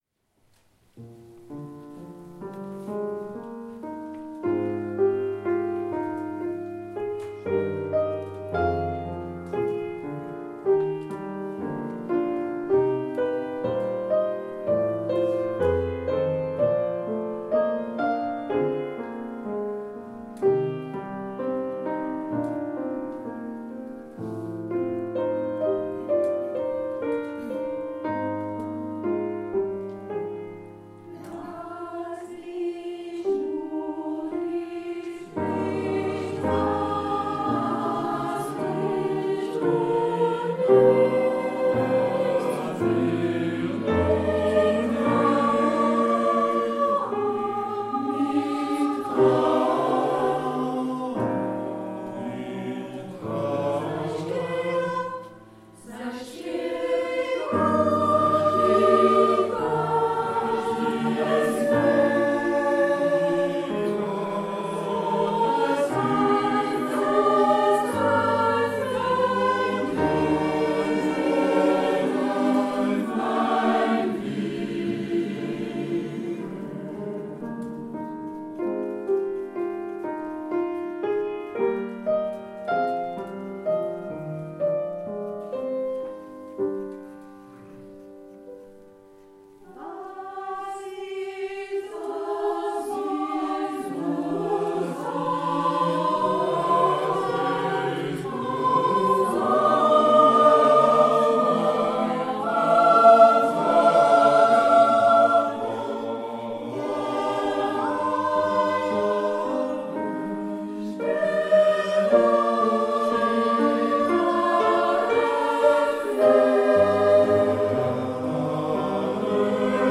Concert Centre Alfred-de-Vigny de Voisins le Bretonneux 6 juin 2015